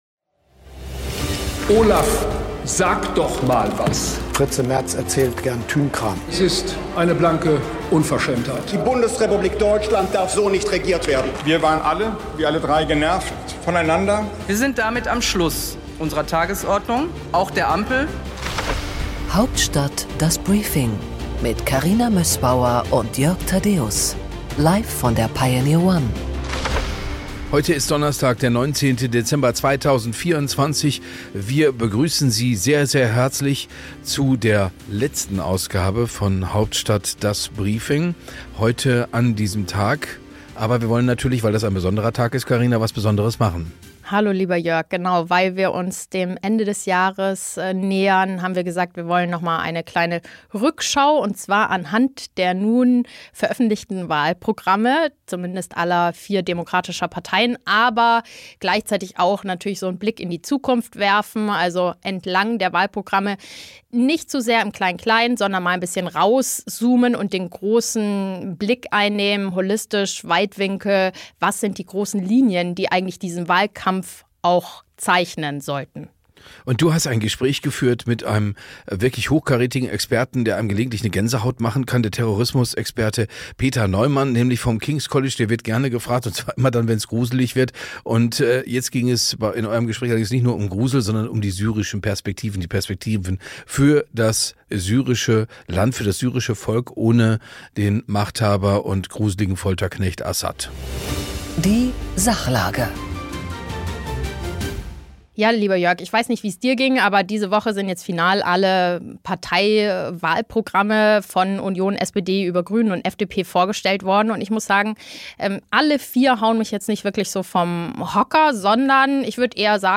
Im Interview der Woche